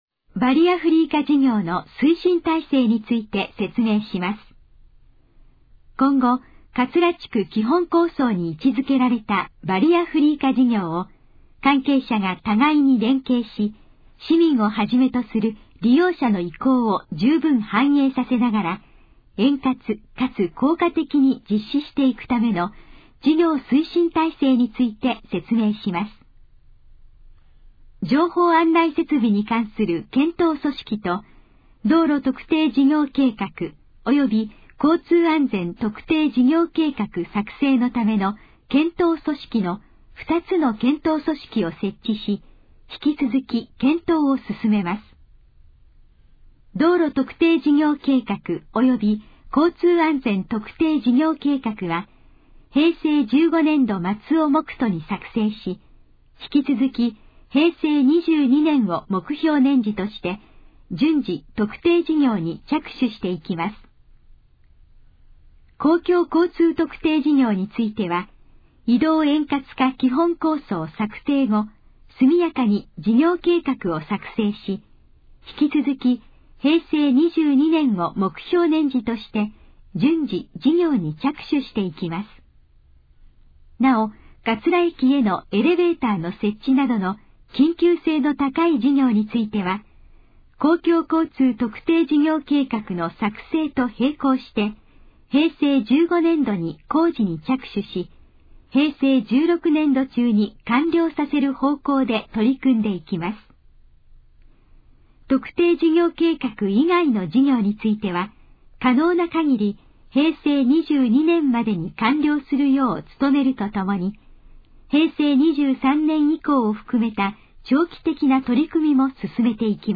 このページの要約を音声で読み上げます。
ナレーション再生 約322KB